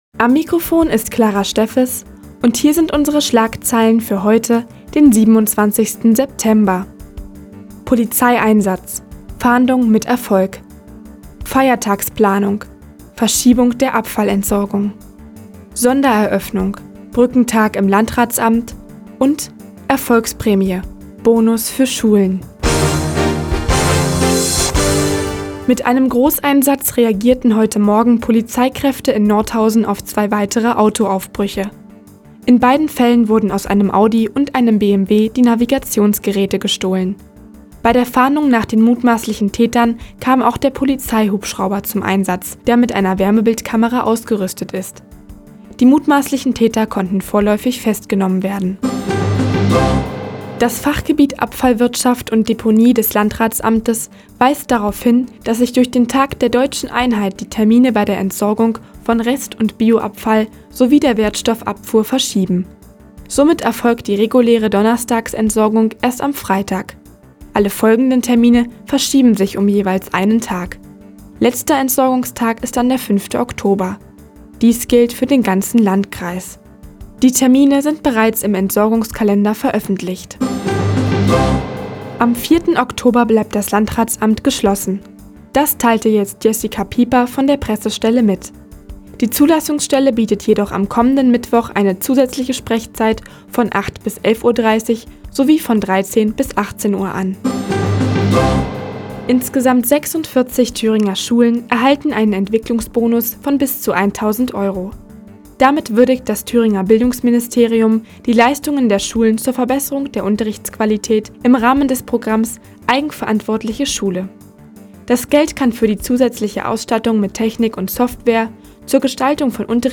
Die tägliche Nachrichtensendung des OKN ist jetzt hier zu hören.